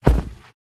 Minecraft Version Minecraft Version snapshot Latest Release | Latest Snapshot snapshot / assets / minecraft / sounds / mob / polarbear / step4.ogg Compare With Compare With Latest Release | Latest Snapshot